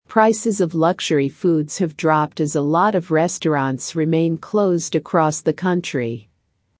ディクテーション第1問
【ノーマル・スピード】
❖ foods (h)ave: have の h が脱落気味に発音され、その後のｖも子音止めなので、foodsa のように聞こえる